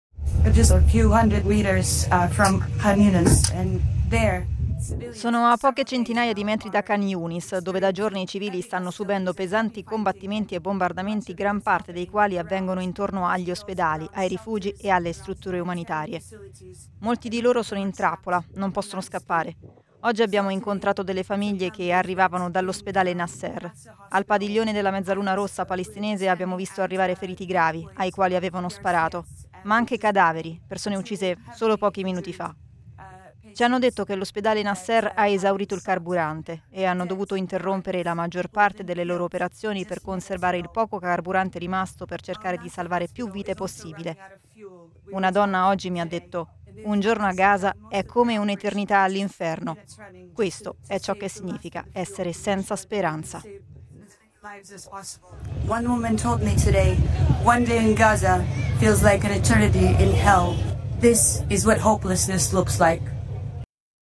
OCHA-1930-Testimonianza-da-Gaza-doppiata.mp3